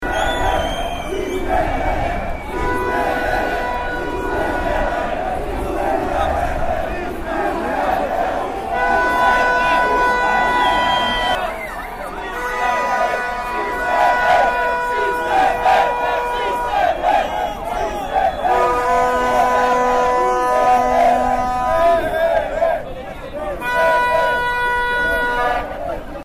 Absolvenții promoției 2017 au strigat în cor USV
Ieri, s-a auzit numele universității noastre în toată Suceava.
Aceștia au mărșăluit din Parcul Universității până pe Esplanada Casei de Cultură.
Studenții au fost îmbrăcați în tricouri personalizate și robe, fluturând  steaguri și stigând în cor numele universității.